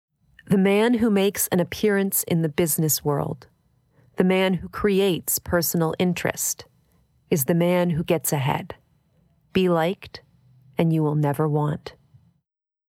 Obviously, the actor for Example A is a cis woman with a Canadian accent.
Example A: Average, Average, Soft, Average, Average
DOAS_FEMALE_01.mp3